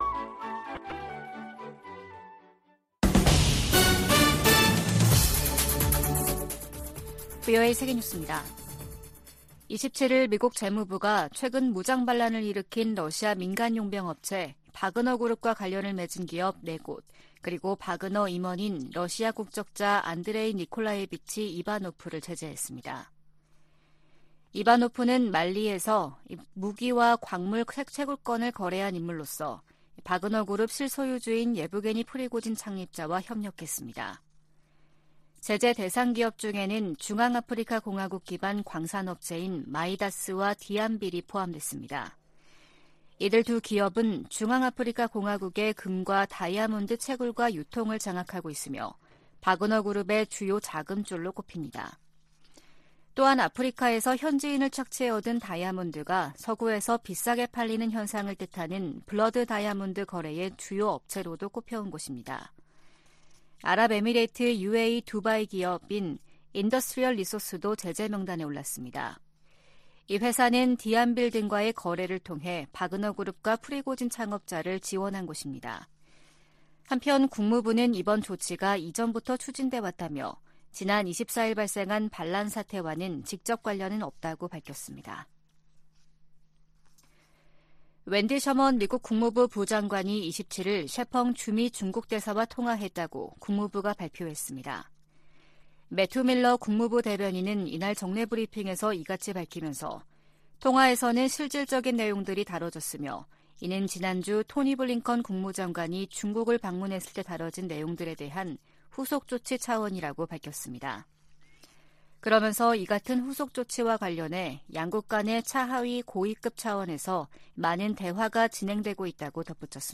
VOA 한국어 아침 뉴스 프로그램 '워싱턴 뉴스 광장' 2023년 6월 29일 방송입니다. 미 국무부는 미국과 한국의 군사활동 증가와 공동 핵계획 탓에 한반도 긴장이 고조되고 있다는 중국과 러시아의 주장을 일축했습니다. 미 국방부는 북한의 핵무력 강화 정책 주장과 관련해 동맹국과 역내 파트너들과의 협력을 강조했습니다. 미 하원 세출위원회가 공개한 2024회계연도 정부 예산안은 북한과 관련해 대북 방송과 인권 증진 활동에만 예산을 배정하고 있습니다.